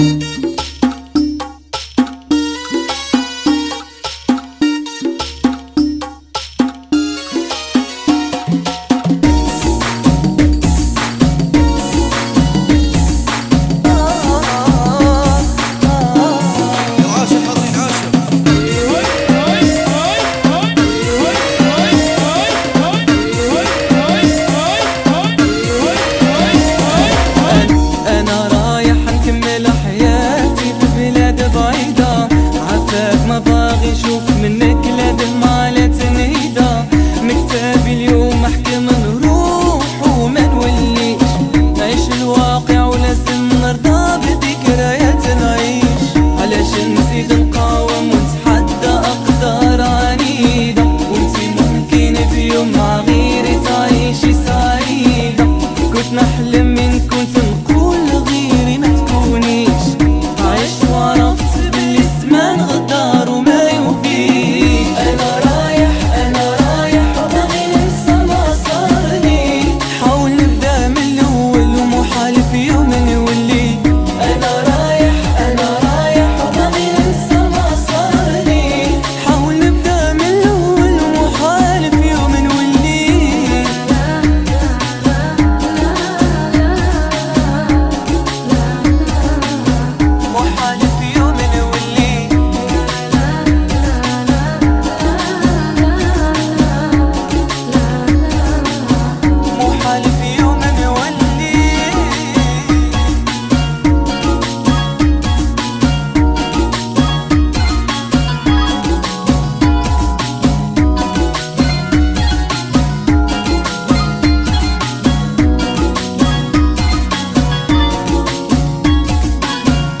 Funky [ 104 Bpm ]